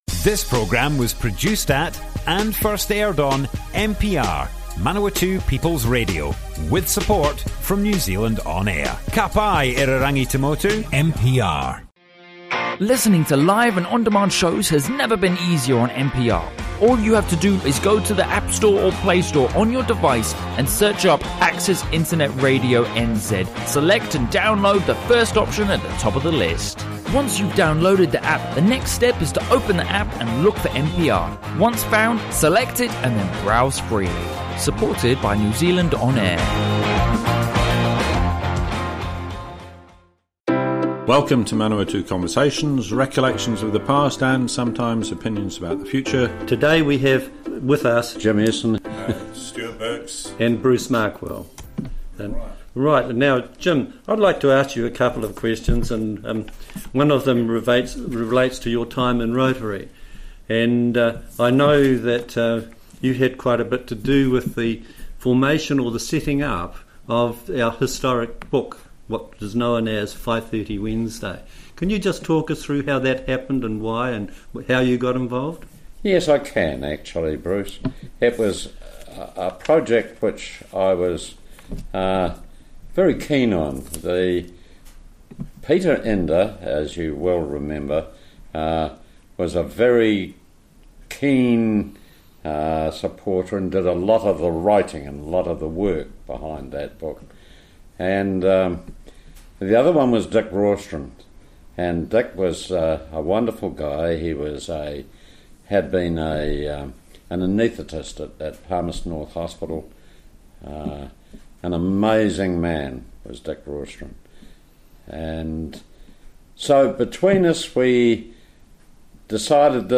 Manawatū Conversations More Info → Description Broadcast on Manawatū People's Radio 23 July, 2019.
oral history